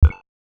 Simple Cute Alert 14.wav